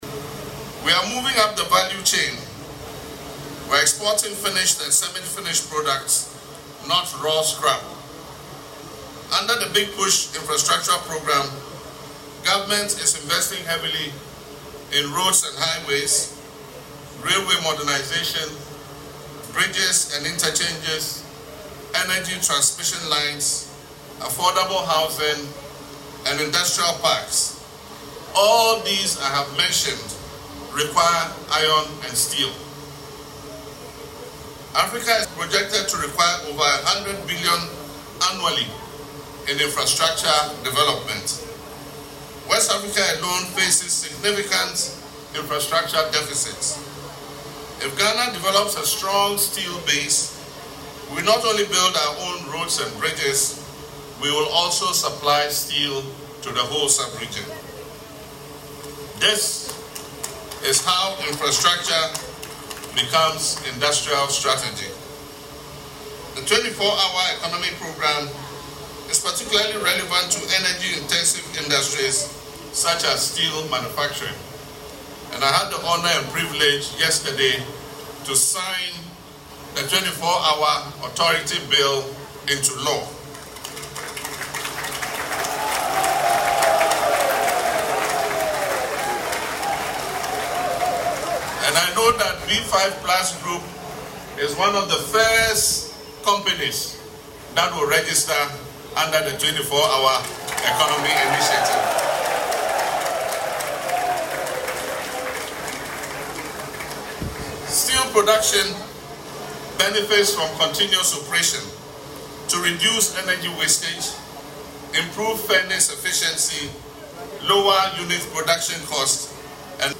The President concluded with a prayer for the success of the investment and formally declared the Phase Two expansion commissioned.
LISTEN TO PRESIDENT MAHAMA IN THE AUDIO BELOW: